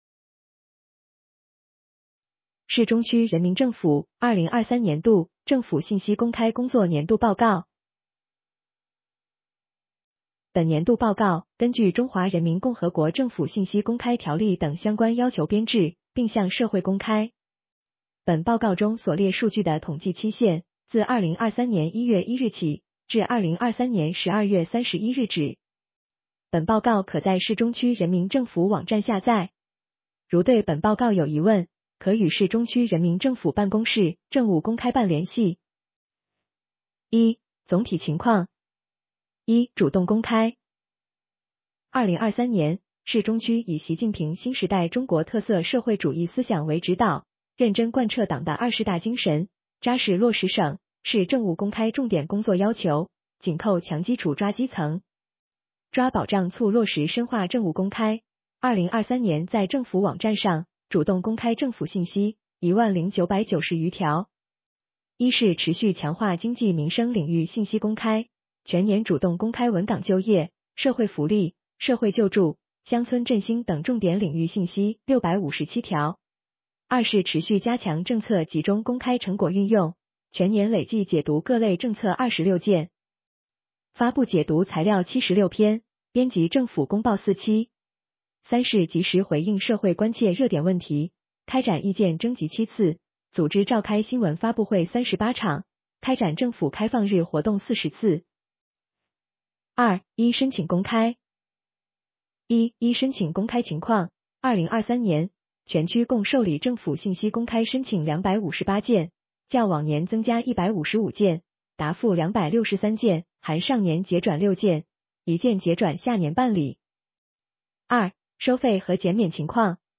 市中区人民政府2023年度政府信息公开工作年度报告.docx 市中区人民政府2023年度政府信息公开工作年度报告.pdf AI播报：市中区人民政府2023年信息公开工作年度报告.mp3